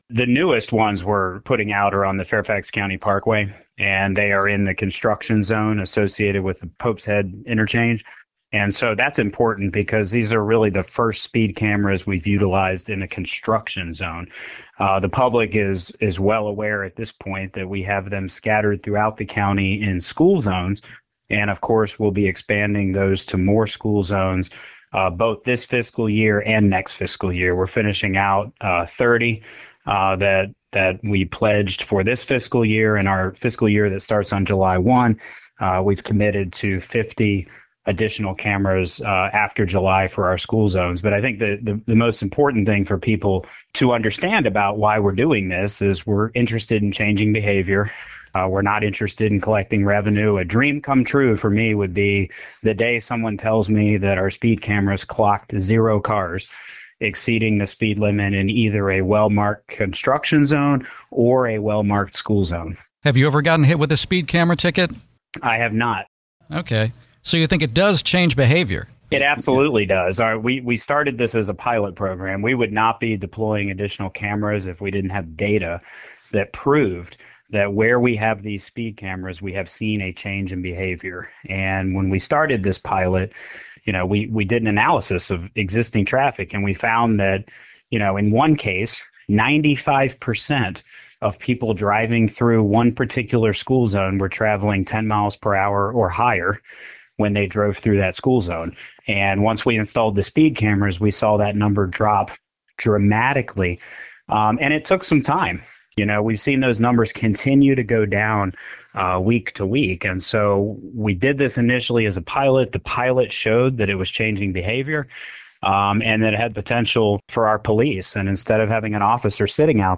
Jeff McKay, chairman of the Fairfax County Board of Supervisors, tells WTOP that more speed cameras will be installed in the Virginia county.